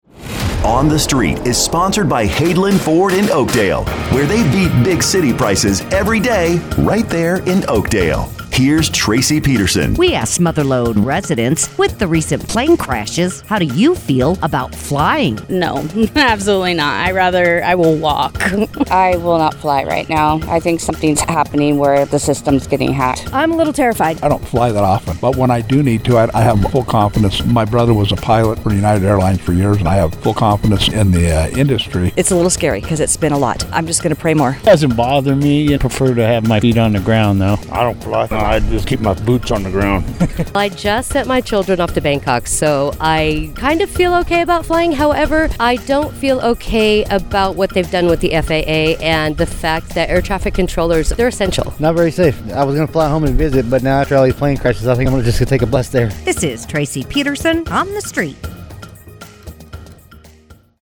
asks Mother Lode residents, “With the recent plane crashes, how do you feel about flying?”